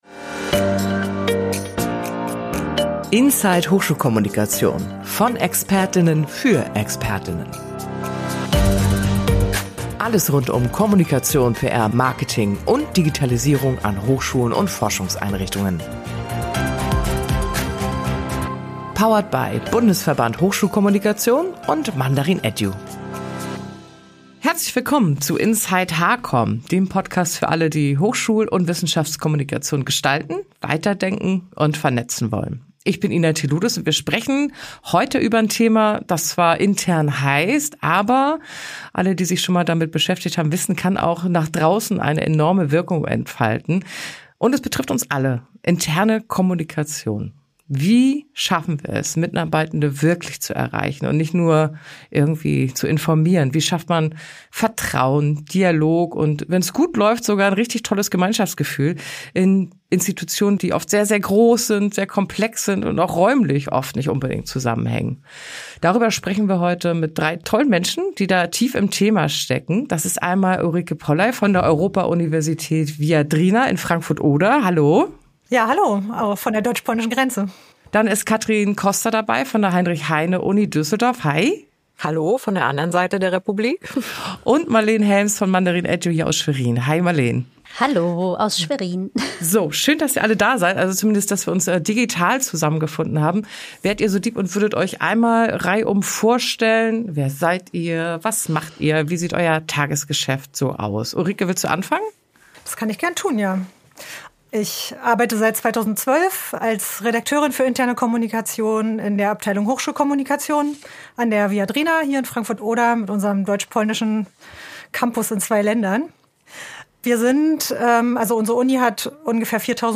Darüber sprechen wir mit drei Expertinnen, die täglich an diesem Thema arbeiten und unterschiedliche Blickwinkel mitbringen.